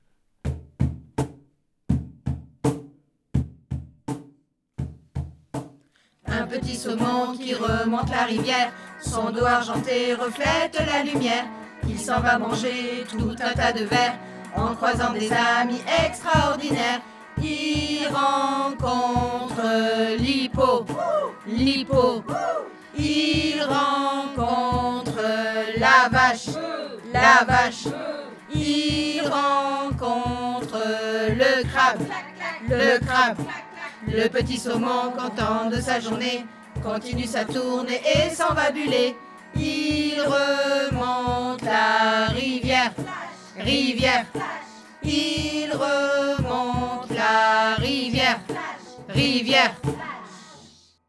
Des comptines pour les petits :
7 assistantes maternelles du RPE des Côteaux et du Plateau ont  collaboré pour mettre en musique cinq comptines qui ont été mises en musique avec des enseignants de l'école Intercommunale de musique et danse de Arche Agglo, et que vous pouvez retrouver ici.